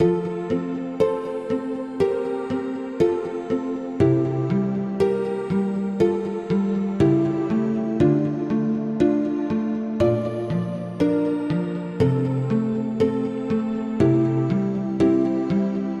描述：我认为这是一个非常可爱和无辜的循环。
在Ohm工作室制作。
Tag: 120 bpm Ambient Loops Synth Loops 2.69 MB wav Key : F